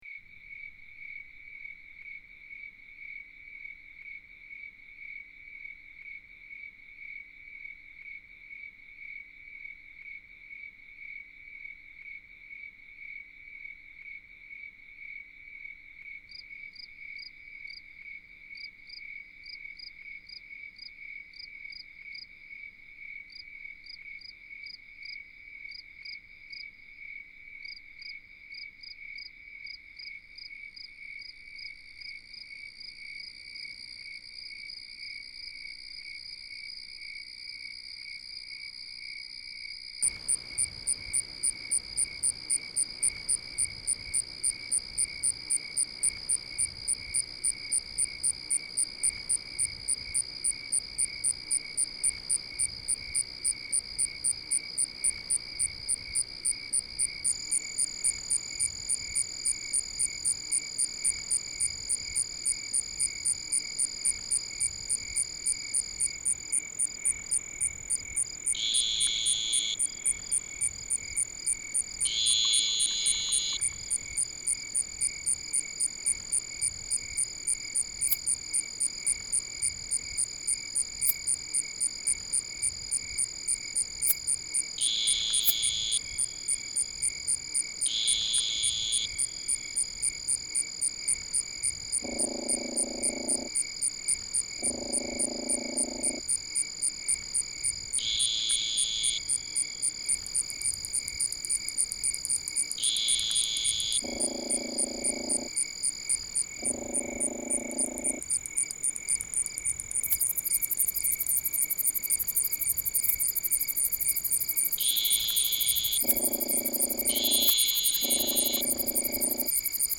A Two-spotted tree cricket (Neoxabea bipunctata) utters short, piercing cries, usually in sonic pairs, sometimes in series of threes or fours.
My foot has been tapping since the tree crickets started calling, and now, with the strong beat of the True katydid, I can’t help but imagine melodic lines filling the spaces in between the pulses.
A sonogram of a composite recording of most of the orthopteran species singing in my garden. On a good night I can hear them all, but here I decided to add them one by one to the recording to make each species’ song stand out.
The human musical element near the end was a surprise, but it works!